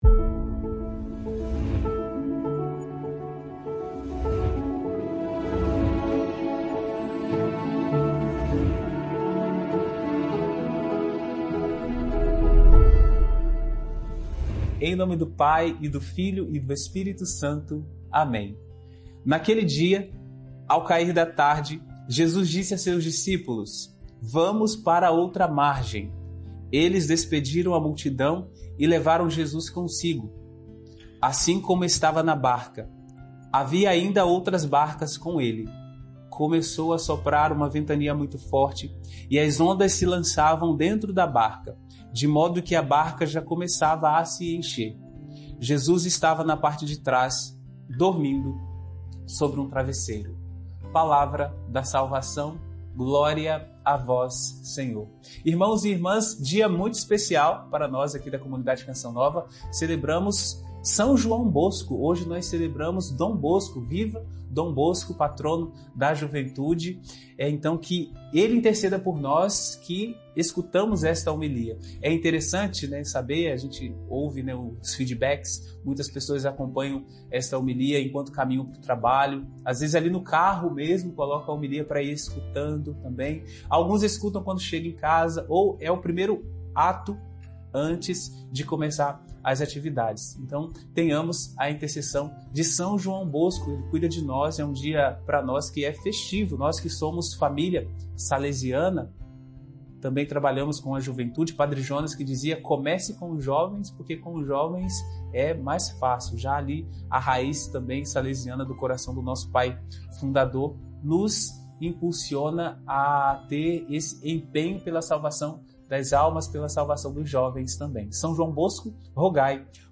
Pedimos, hoje, a intercessão de Dom Bosco por todos que acompanham esta homilia em sua rotina — no carro, no trabalho ou em casa.